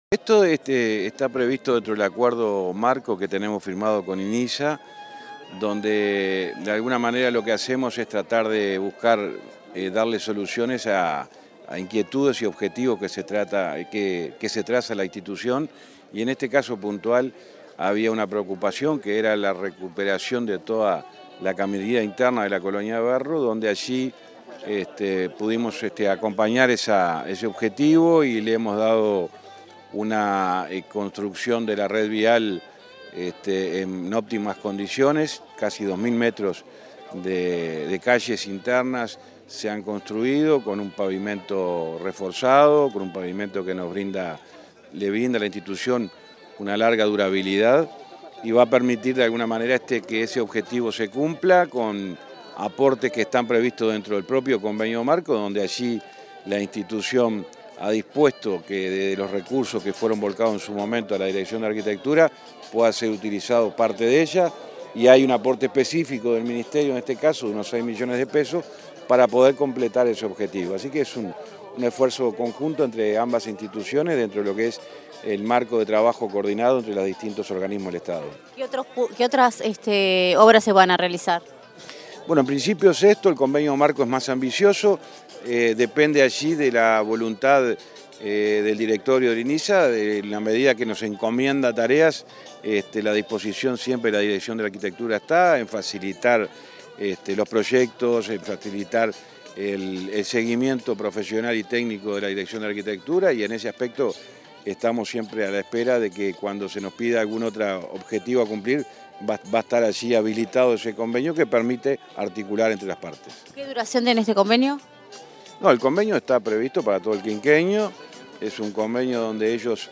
Entrevista al ministro de Transporte y Obras Públicas, José Luis Falero
Entrevista al ministro de Transporte y Obras Públicas, José Luis Falero 29/03/2023 Compartir Facebook X Copiar enlace WhatsApp LinkedIn Tras la inauguración de la pavimentación de la caminería de la Colonia Berro, este 29 de marzo, Comunicación Presidencial dialogó con el ministro de Transporte y Obras Públicas, José Luis Falero.